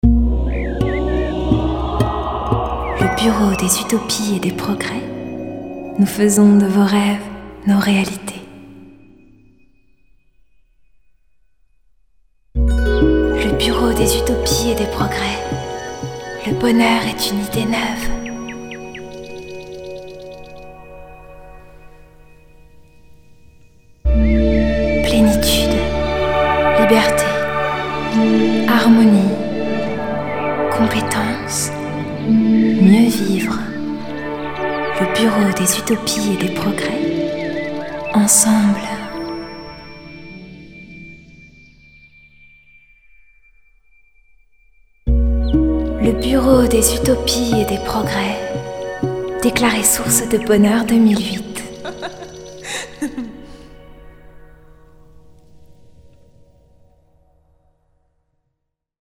Série de jingles (00:59)